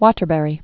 (wôtər-bĕrē, wŏtər-)